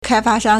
开发商 (開發商) kāifā shāng
kaifa1shang1.mp3